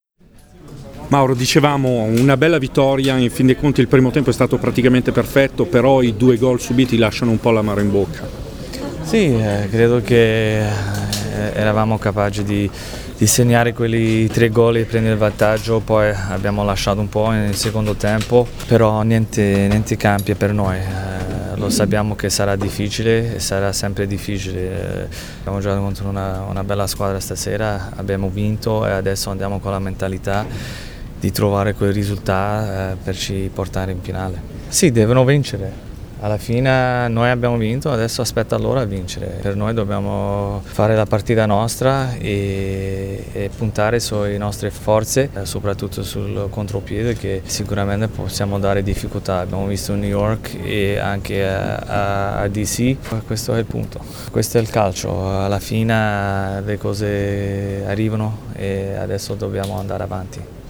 Le interviste del post partita: